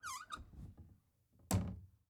Cupboard Door